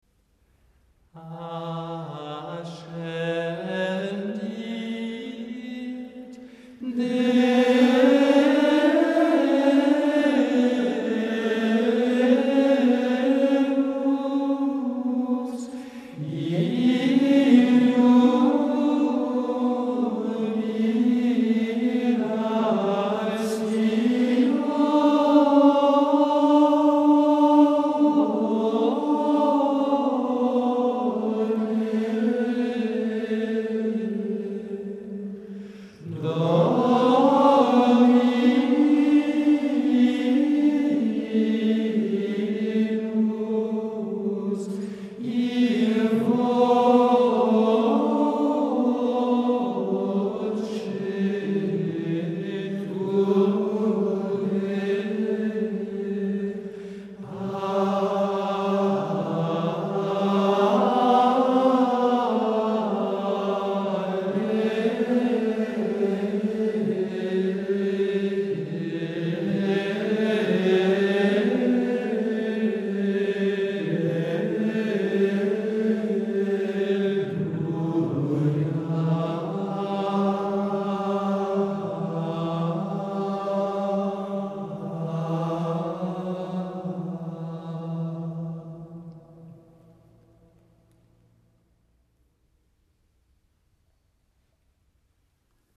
• grégorien ascension communion agnus graduel sanctus gloria
La messe de l’ascension est riche d’expression musicale : l’introït est enthousiaste mais très léger, filant même ; les deux alléluias sont profonds et contemplatifs, beaucoup plus recueillis ; l’offertoire, lui, est un chant puissant, chaleureux, sonore, vibrant.
Il faut y mettre de l’ardeur et de la voix.
Les deux mots ascéndit Deus nous font parcourir toutes les notes de l’octave, à partir du Ré initial, jusqu’au Ré aigu de Deus.
L’élan est réel et la largeur vient surtout du crescendo progressif qui accompagnera cette montée très rythmée mais souple.
Le reste de la vocalise de Deus est beaucoup plus fluide, avec une nuance de complaisance et de tendresse, sans toutefois que la mélodie perde sa fermeté, bien rendue jusqu’au bout grâce aux Si naturels qui précèdent la cadence.
La voix, dès la quinte initiale, s’appuie bien sur le Ré grave, puis monte avec légèreté jusqu’au Do aigu, avant de se détendre en un très beau mouvement bien balancé sur les deuxième et troisième syllabes de jubilatióne.